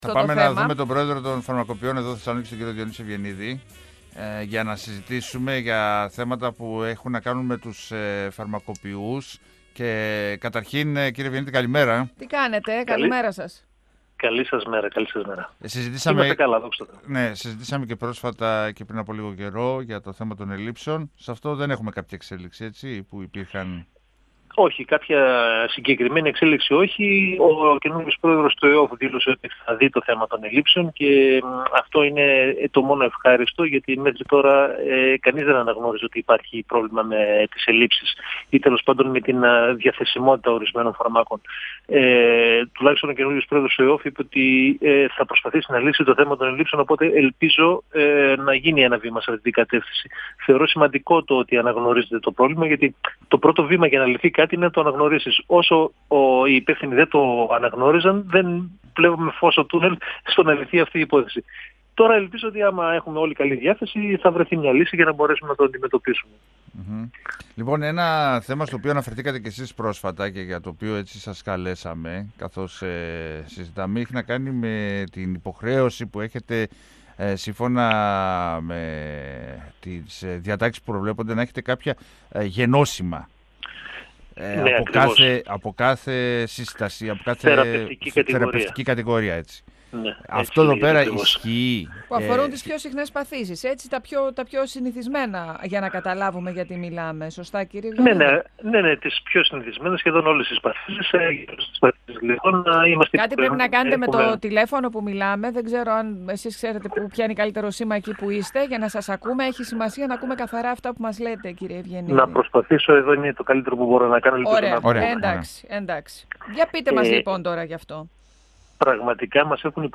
στον 102 fm της ΕΡΤ3